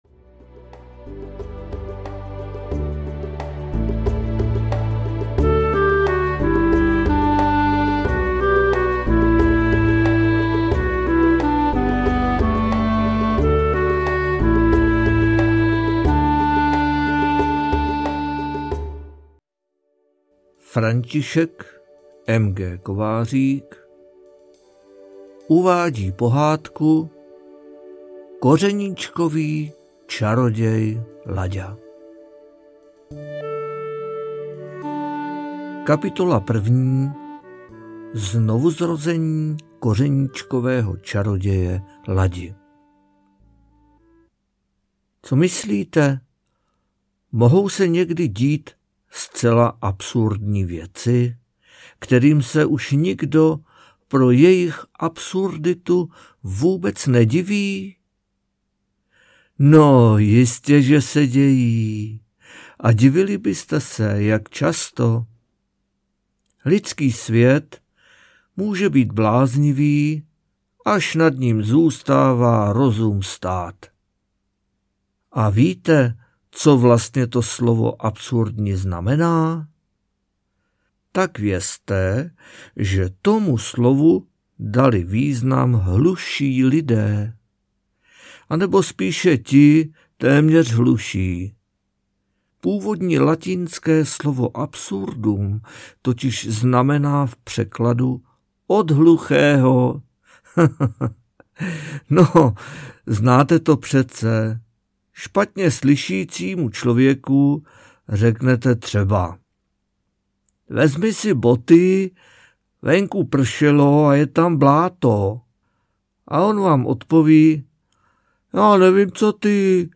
Kořeníčkový čaroděj audiokniha
Ukázka z knihy